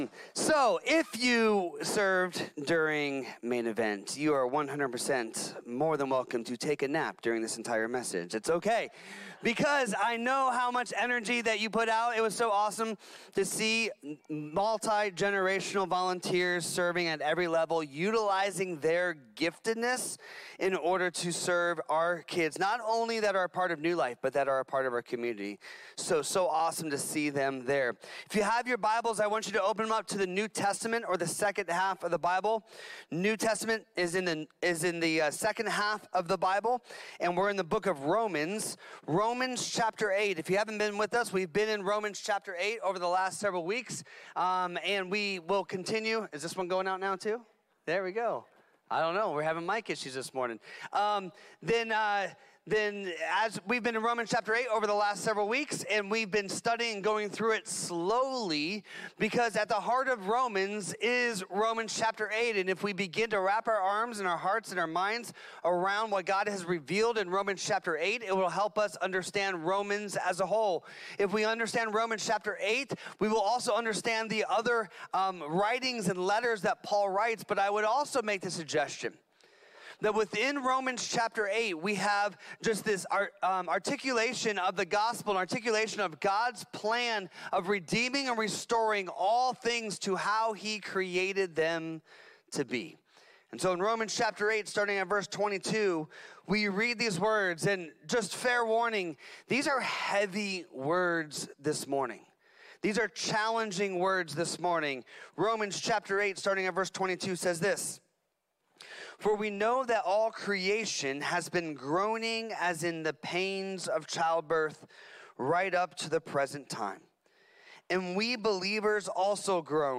A message from the series "Romans 8."